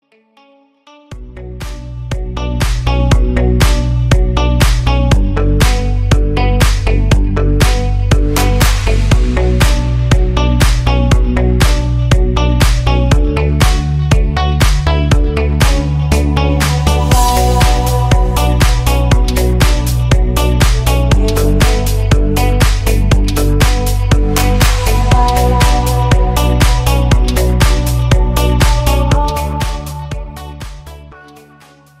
ритмичные
мелодичные
женский голос
Стиль: deep house